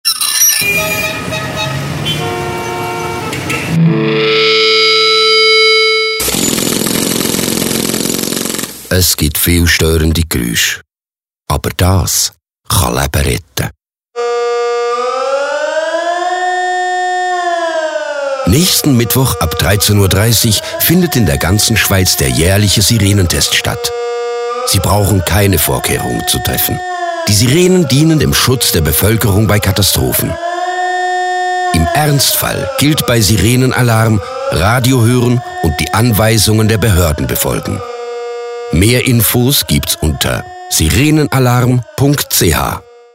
Radio-Spot (Zur Ausstrahlung bis am Tag vor dem Sirenentest)